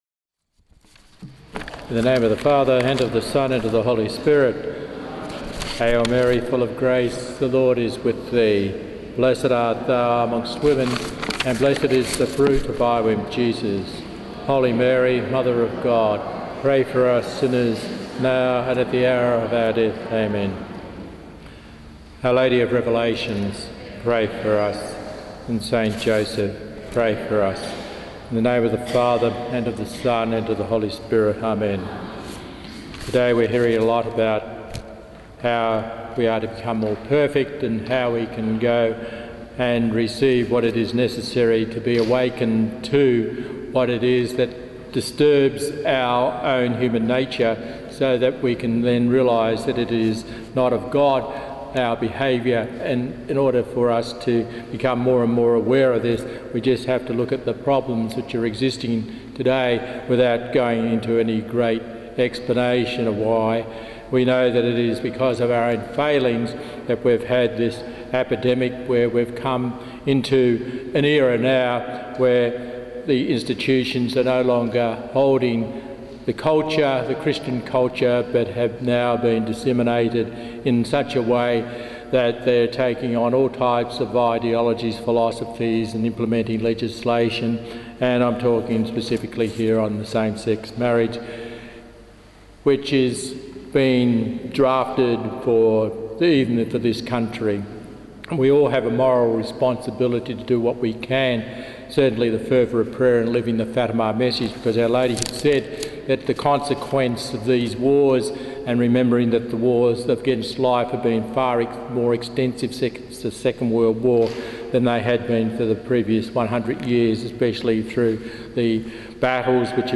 speaks on Our Lady during the “Day With Mary” held at St. Patrick’s Basilica in Fremantle, Western Australia on 4 July 2015.